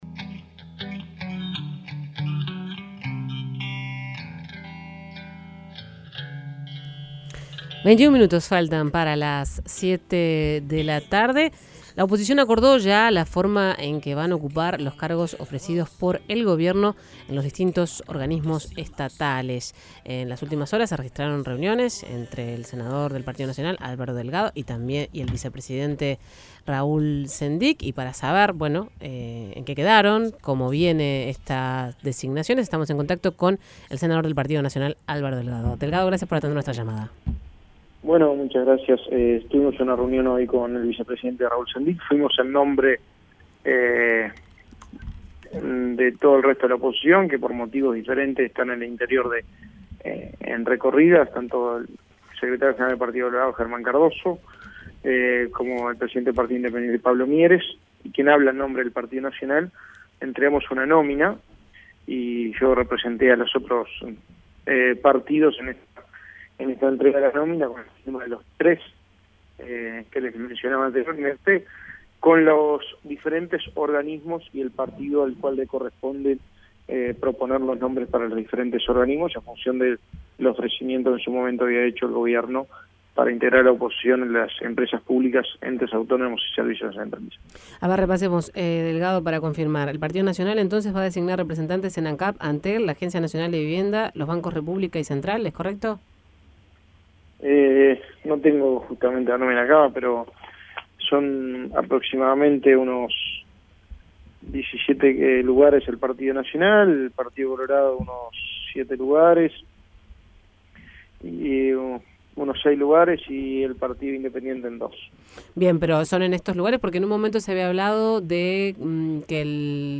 El senador del Partido Nacional, Álvaro Delgado, en conversación con El Espectador dijo que a esa corriente política se le adjudicaron 17 cargos, al Partido Colorado seis y al Partido Independiente dos.